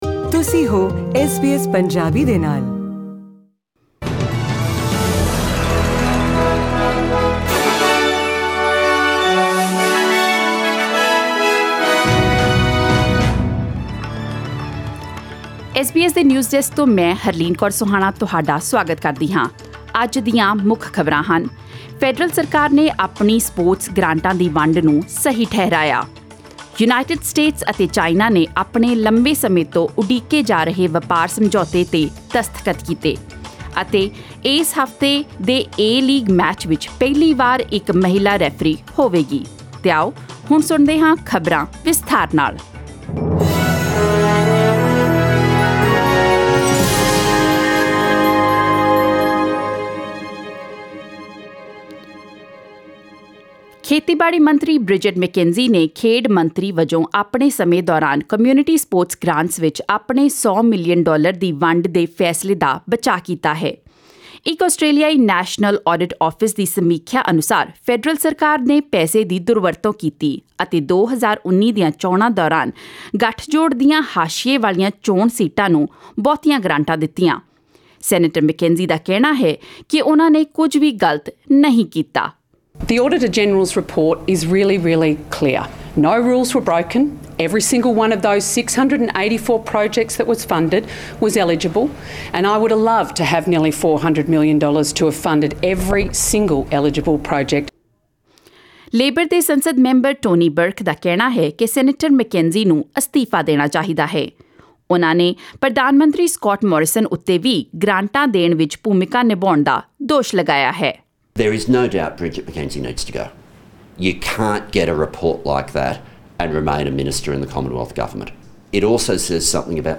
SBS Punjabi News: January 16, 2020